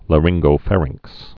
(lə-rĭnggō-fărĭngks)